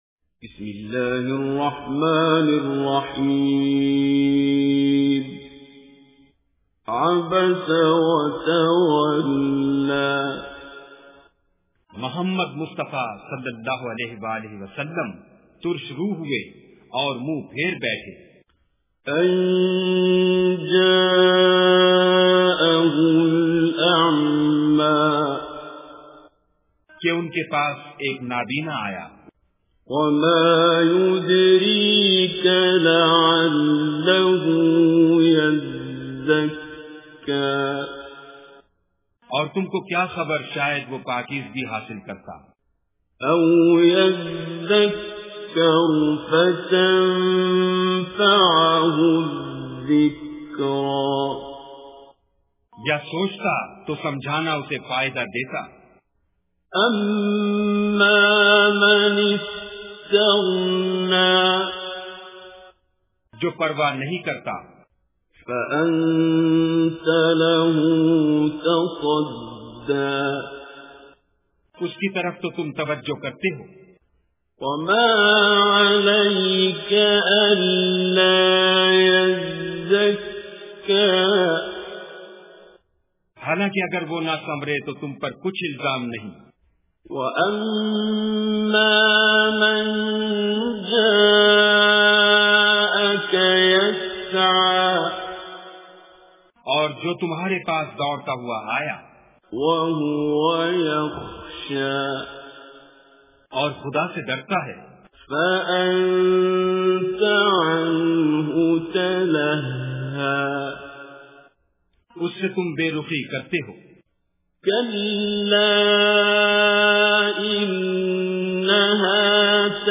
Surah Abasa Recitation with Urdu Translation
Surah Abasa is 80th chapter of Holy Quran. Listen online and download mp3 tilawat / recitation of Surah Abasa in the voice of Qari Basit As Samad.